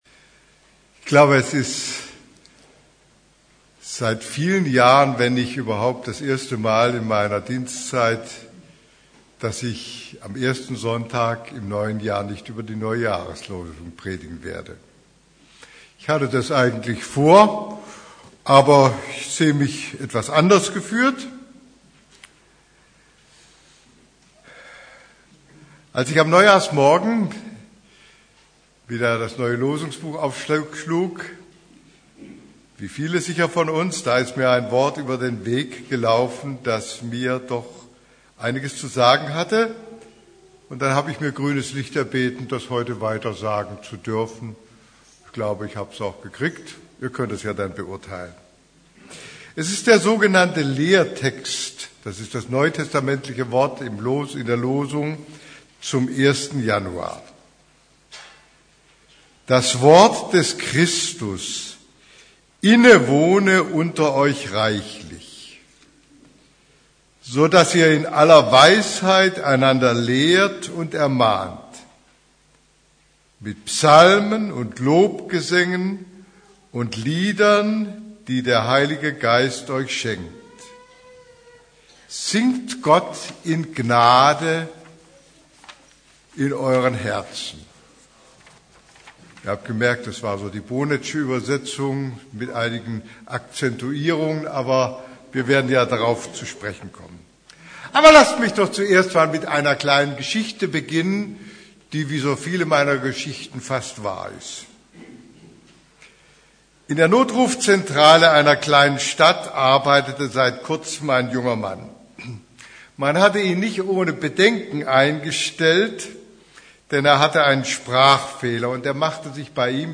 Predigt vom 03.